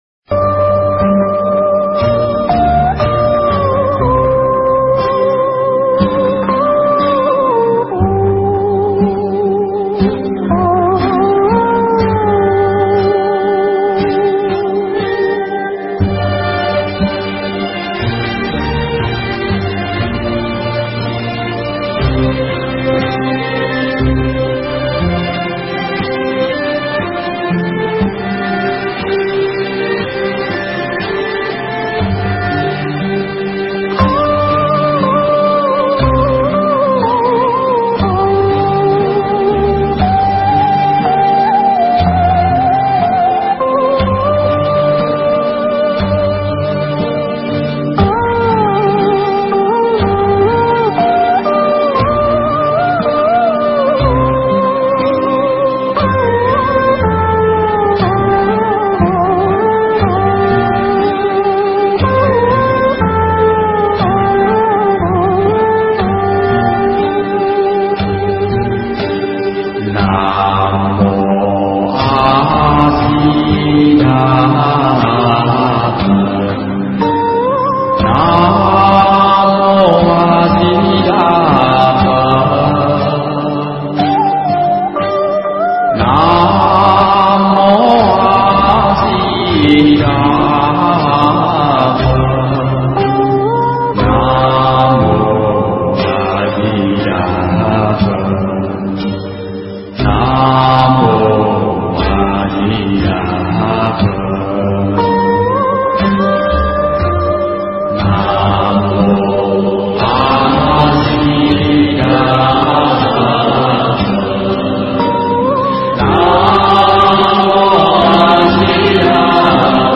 thuyết giảng tại Tu Viện Trúc Lâm, Canada, sinh hoạt phật pháp hằng tuần